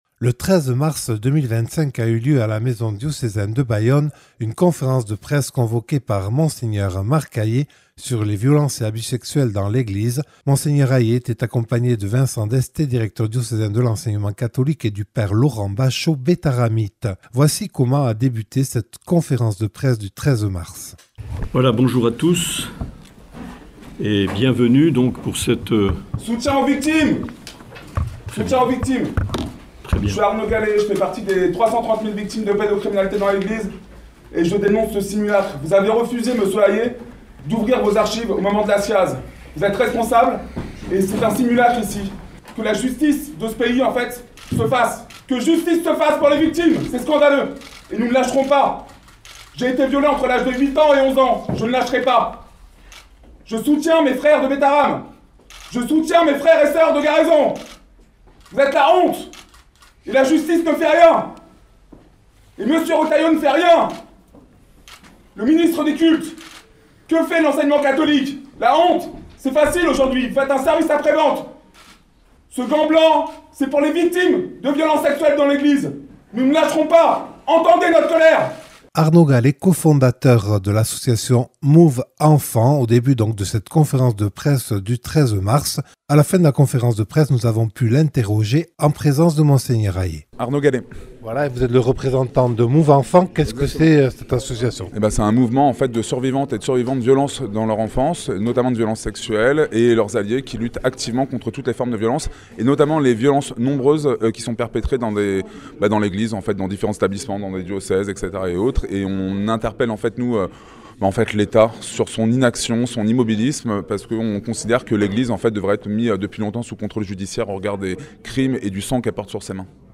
Réactions recueillies à la fin de la conférence de presse